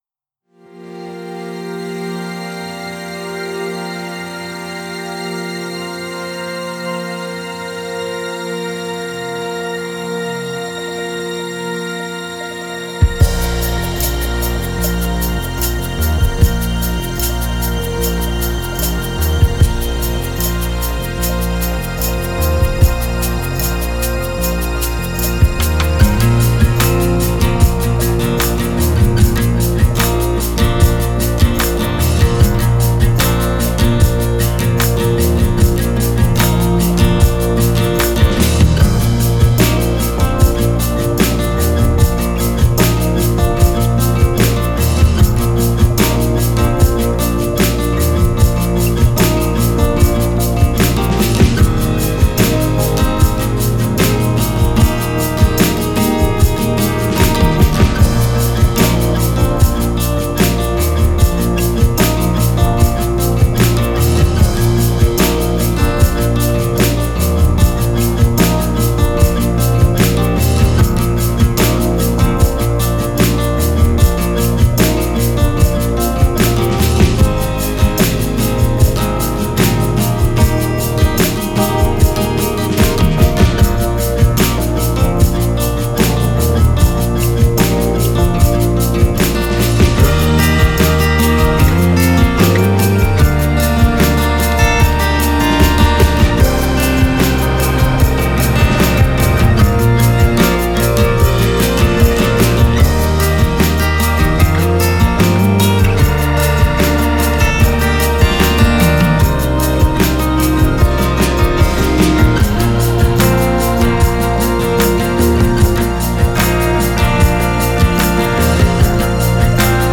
Luckily I stemmed out the Audio files and I gave the song a do-over in Mixbus 10 with a fresh drum track (AVL Blonde Bop this time).
‘Space Folk’…? Probably the most MIDI heavy project I’ve ever done… Lots of Loomer Aspect synth patches setBfree and a couple of guitars tuned to FGDGBD…
The cymbals are very warm.
I did quite a bit of panning to put the kit across the stereo spectrum and a bit of EQ on the kick and snare only and various degrees of compression and some reverb from a Mixbus with Fabfilter R2 on it.
That’s a lovely wee tune with a very nice, warm, vibey feel - particularly like how the guitar(s?) and groove keep everything gently moving along as the melodies develop.